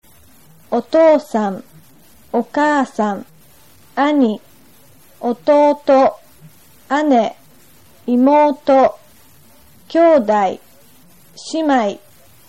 Japanese Lesson Address WAV Sound